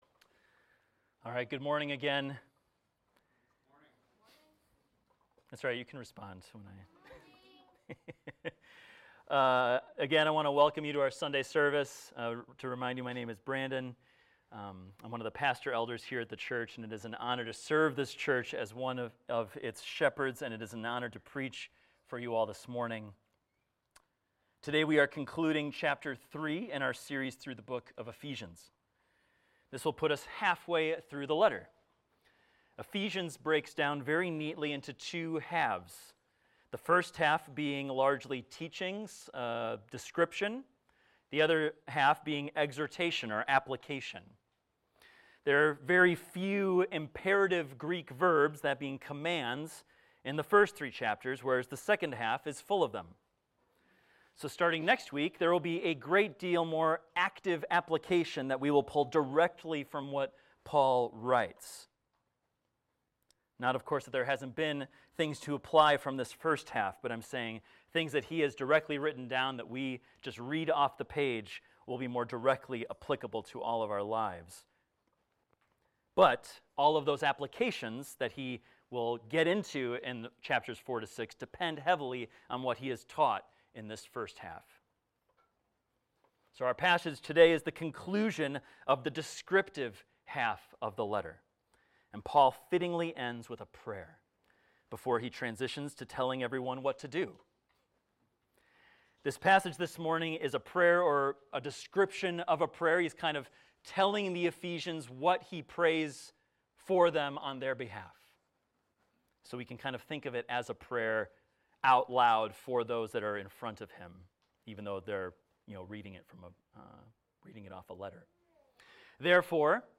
This is a recording of a sermon titled, "To Him Who Is Able."